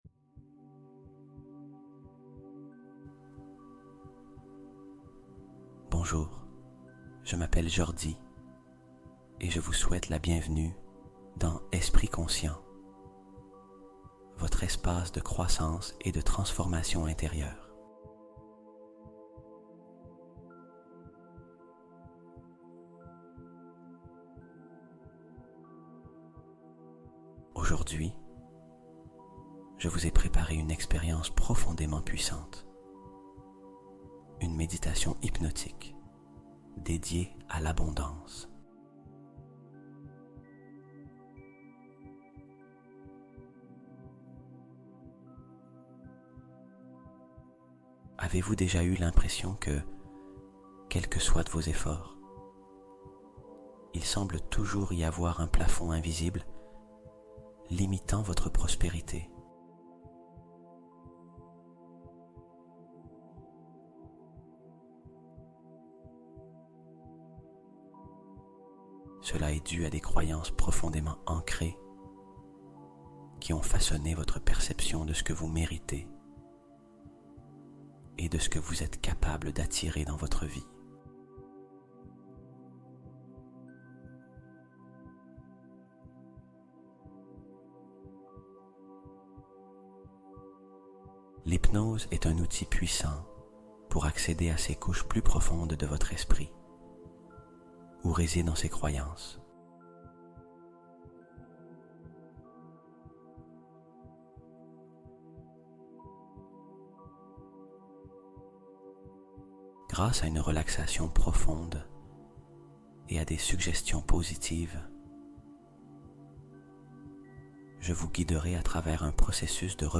Cette hypnose attire TOUT ce que tu désires : argent, amour et sagesse cosmique